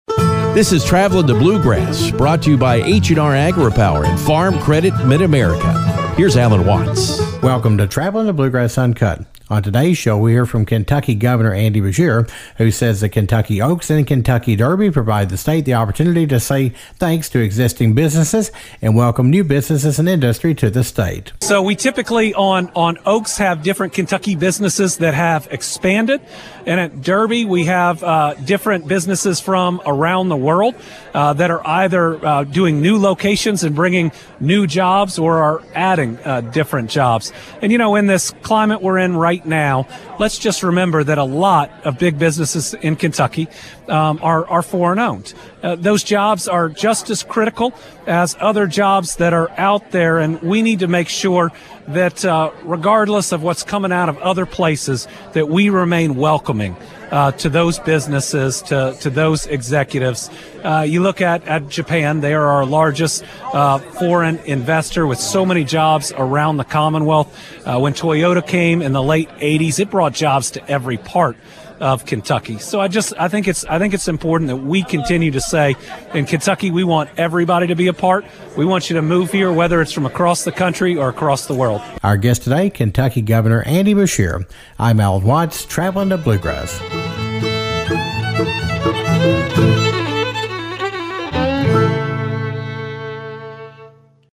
Kentucky Governor Andy Beshear says the Kentucky Oaks and Derby are a great time for the state entertain special business partners from around the country and the world. Governor Beshear says they spend time celebrating existing businesses and entertaining companies that are looking to expand to Kentucky.
5-2-25-ttb-uncut-ky-governor-andy-beshear-ky-derby.mp3